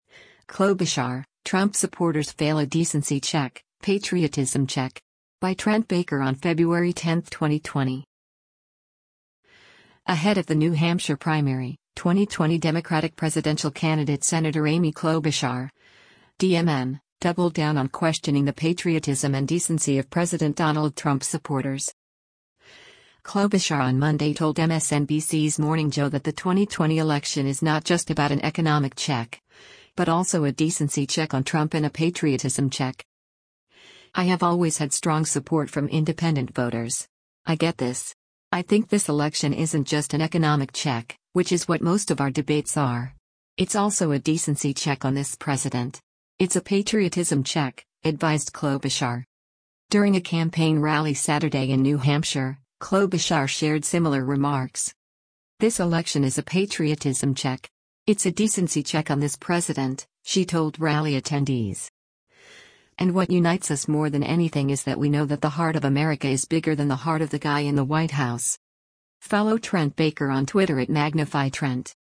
Klobuchar on Monday told MSNBC’s “Morning Joe” that the 2020 election is not just about an economic check, but also a “decency check” on Trump and a “patriotism check.”